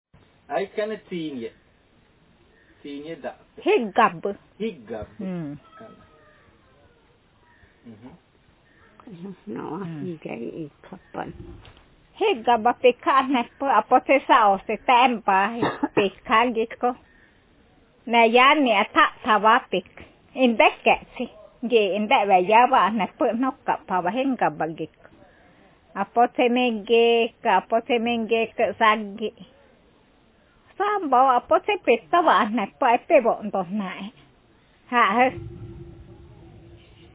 Speaker sexf
Text genreprocedural